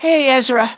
synthetic-wakewords
ovos-tts-plugin-deepponies_Teddie_en.wav